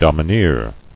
[dom·i·neer]